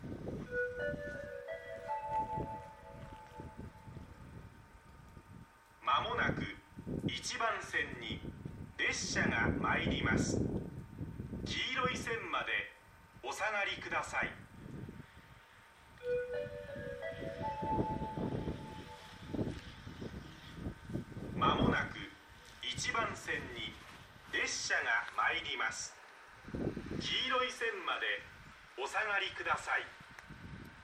接近放送普通　秋田行き接近放送です。